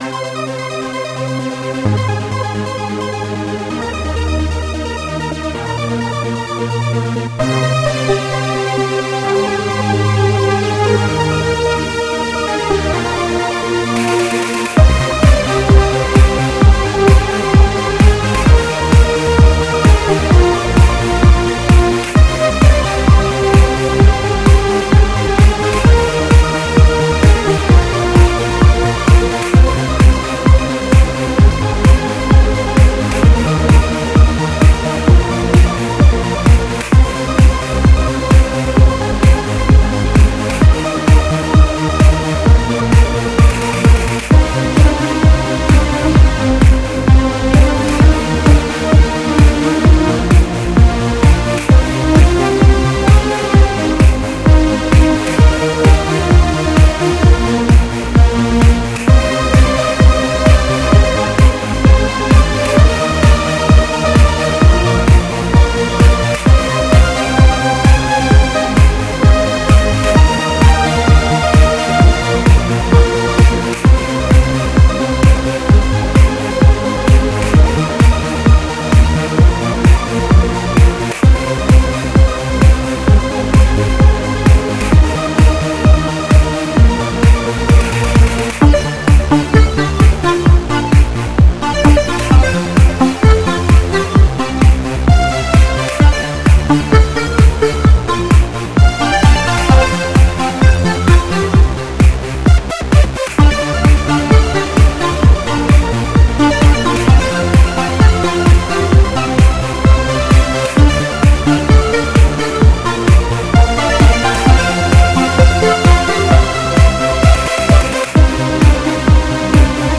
Style: Disco/Dance